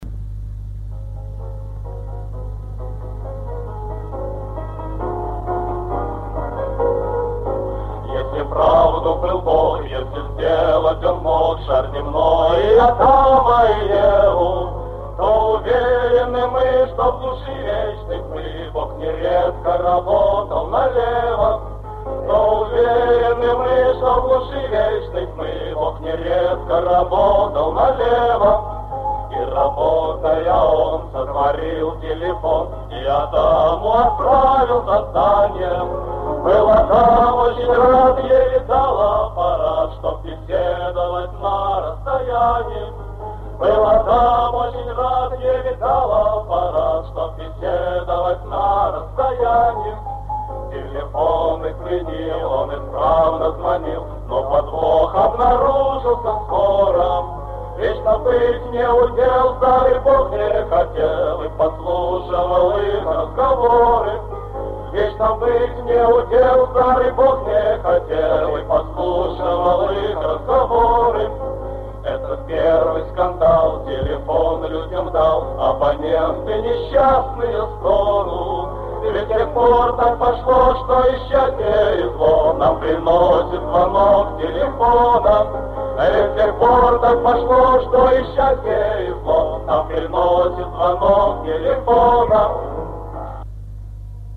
ФРАГМЕНТЫ КОНЦЕРТА-КОНКУРСА 27 АПРЕЛЯ 1959 Г. ДК МЭИ
8. Мужской ансамбль МИСИ